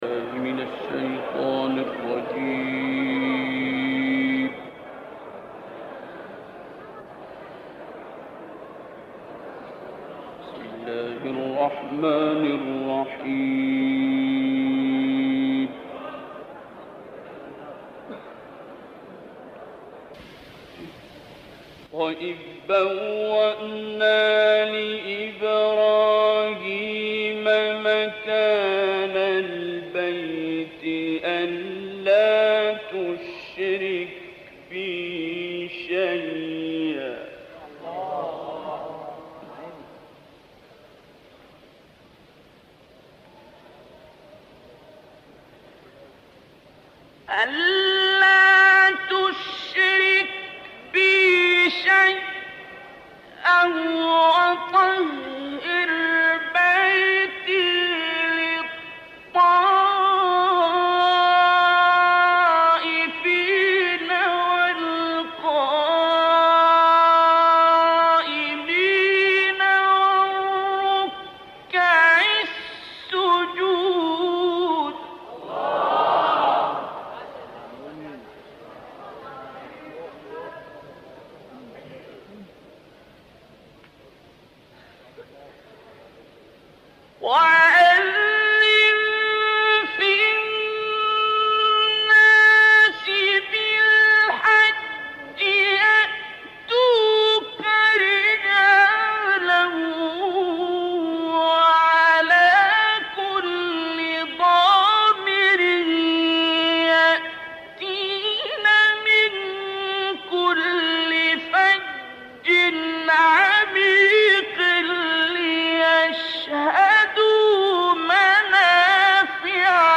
تلاوتی با صدای استاد عبدالباسط عبدالصمد